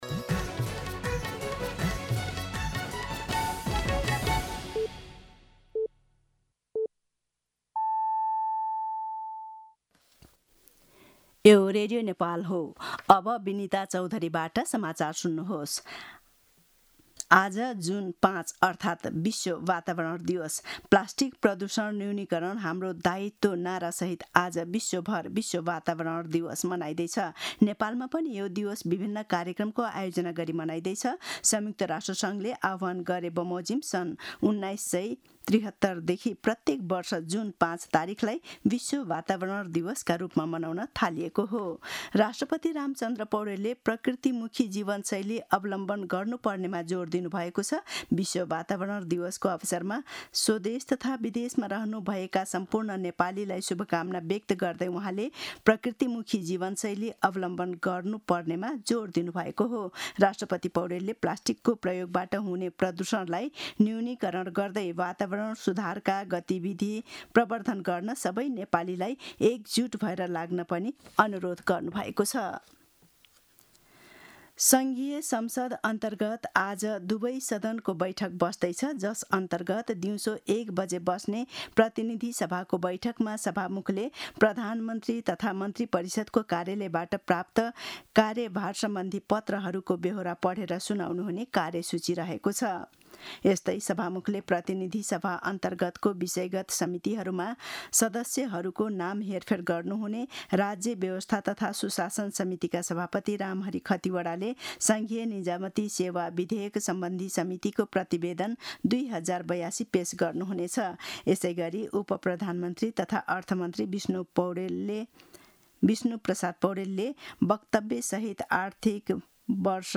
मध्यान्ह १२ बजेको नेपाली समाचार : २२ जेठ , २०८२